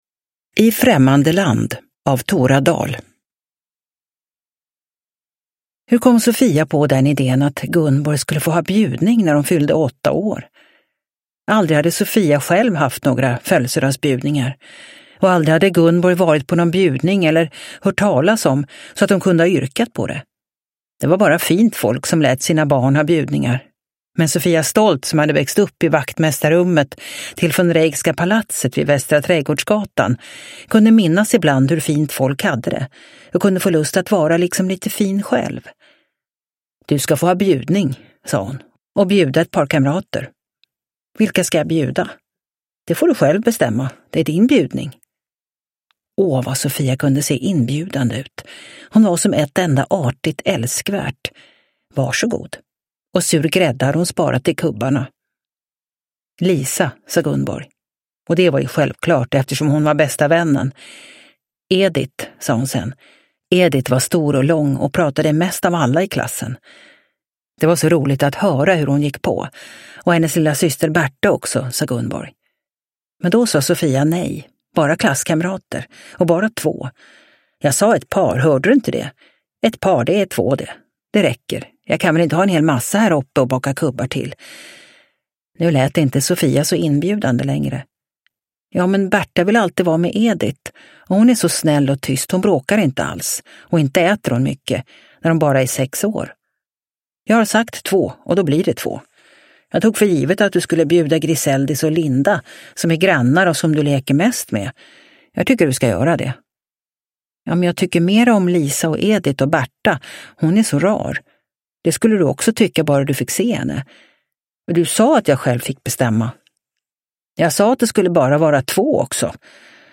I främmande land – Ljudbok – Laddas ner